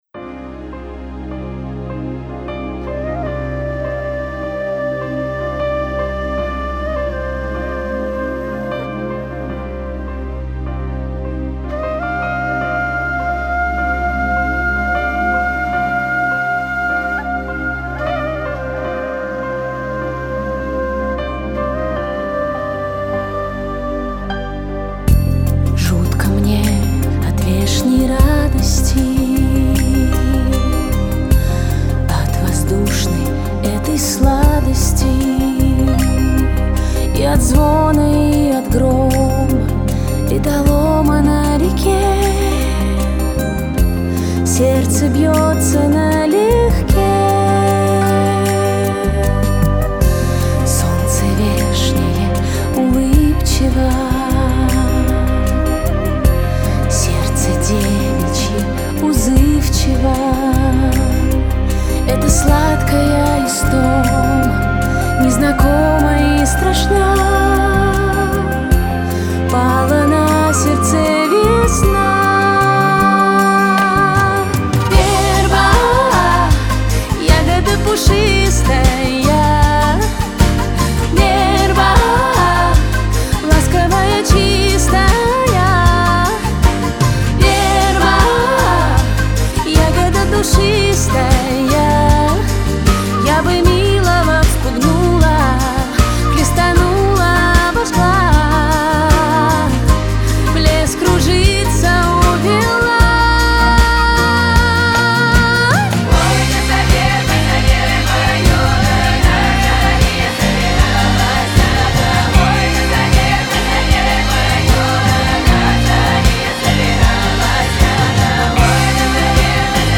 запись народного ансамбля
гитара
бас-гитара
тростниковая флейта, курский и тверской рожок,калюка
партии народного вокального ансамбля